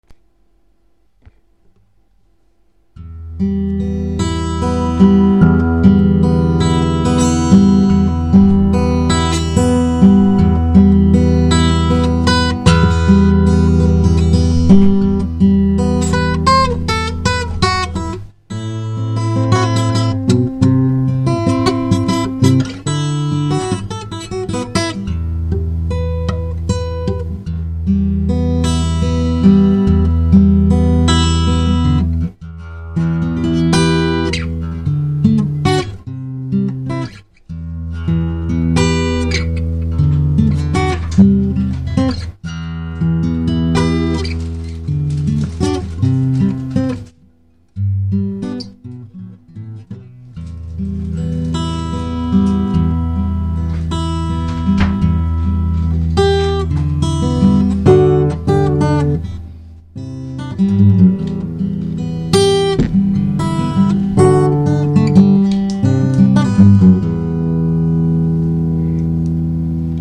• Stop, w którym jest 85% miedzi i 15% cyny. Struny z owijką z takiego materiału łączą wspaniałą charakterystykę brzmienia strun z owijką typu 80/20, z większym sustain (dźwięk dłużej wybrzmiewa), co przypomina cechy strun fosforowo-brązowych